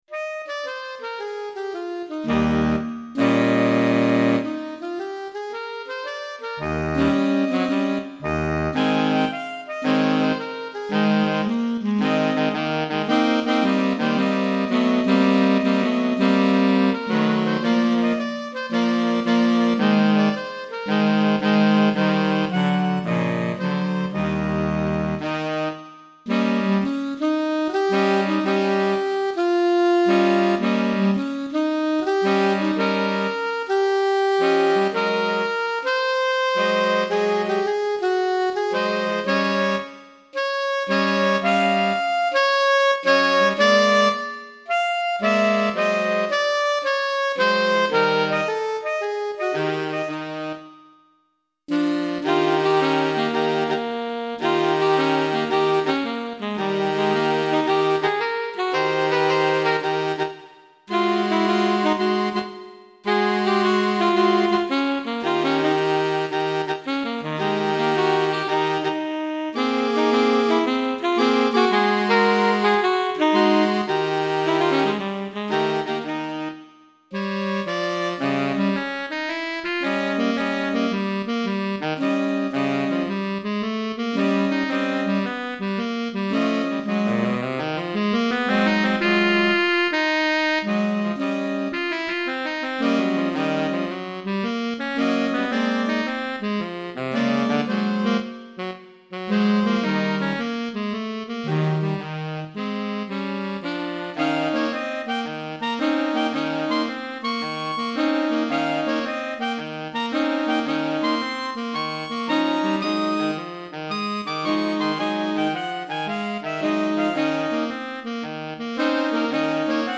2 Saxophones Alto, Ténor et Baryton